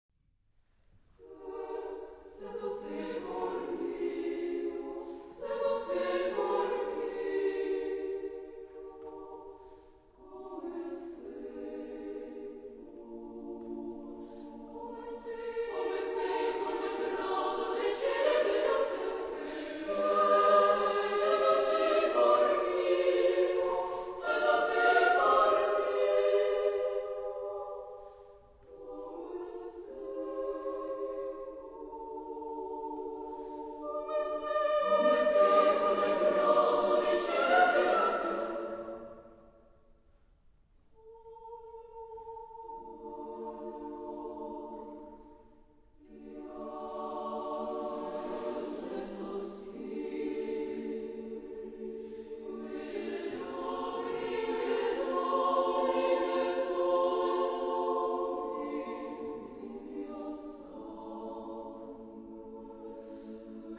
Genre-Style-Form: Madrigal ; Secular
Mood of the piece: moderate
Type of Choir: SSATB  (5 mixed voices )
Tonality: A dorian